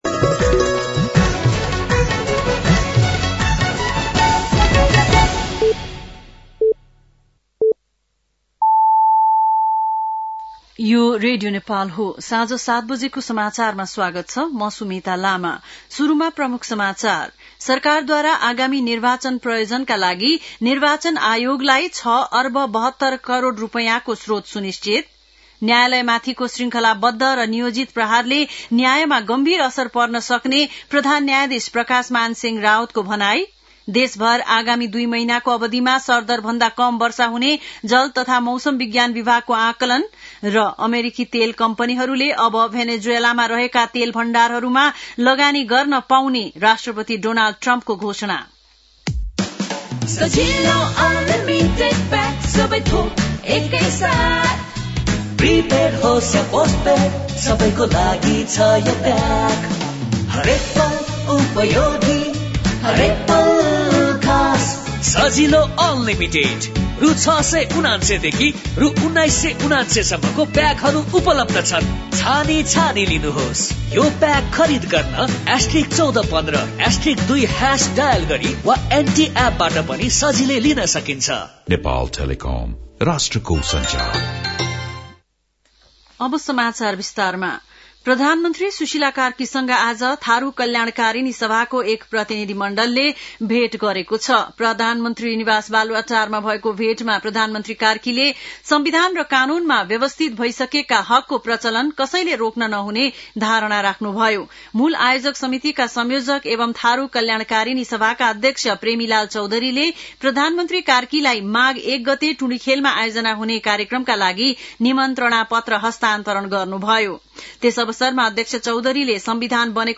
An online outlet of Nepal's national radio broadcaster
बेलुकी ७ बजेको नेपाली समाचार : २० पुष , २०८२
7-pm-nepali-news-9-20.mp3